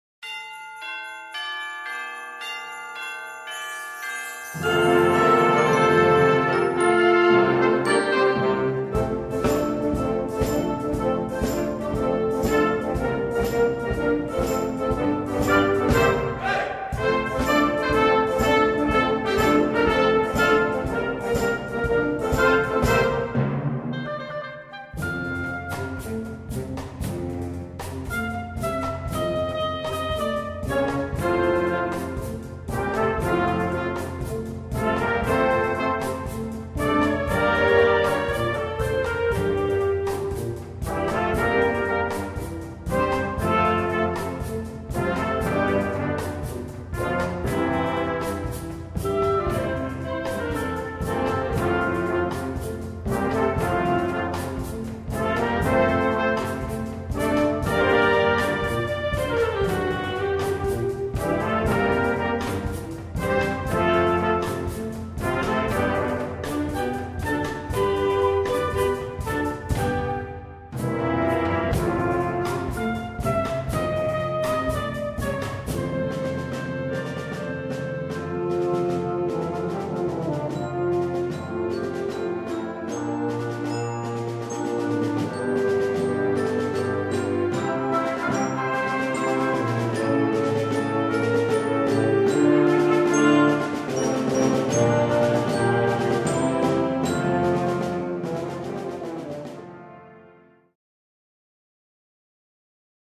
Partitions pour orchestre d'harmonie et - fanfare jeunes.